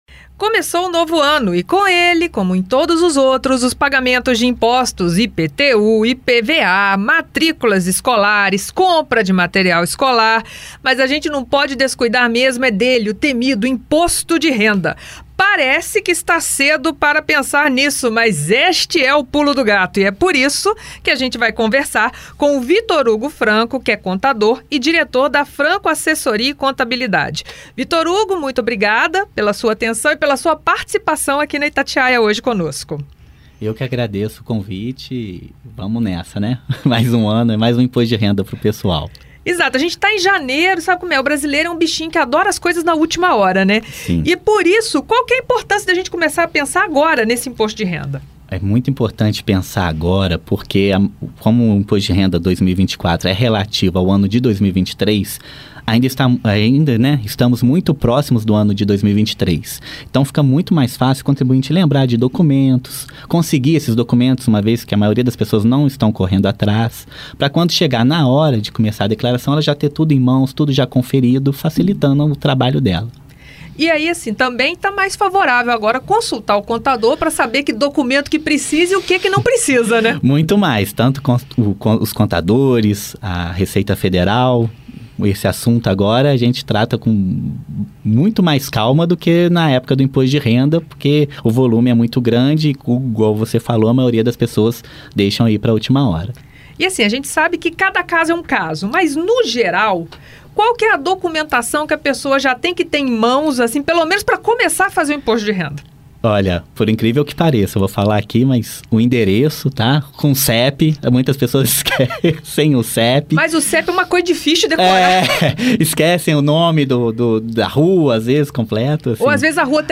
Itatiaia-Entrevista-IR.mp3